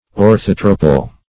Orthotropal \Or*thot"ro*pal\, Orthotropous \Or*thot"ro*pous\, a.